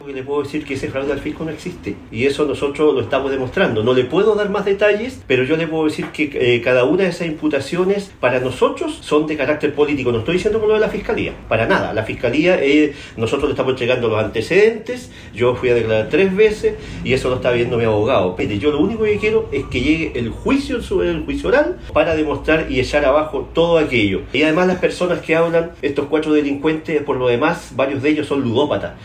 En el comedor de su casa, donde cumple la cautelar de arresto domiciliario total, con lápiz, papel y un vaso de agua, el exalcalde de Puerto Montt, Gervoy Paredes, conversó con Radio Bío Bío y defendió su inocencia afirmando que junto a su defensa tienen antecedentes que desestiman los cuatro delitos de corrupción que se le imputan al momento de liderar la capital regional de Los Lagos.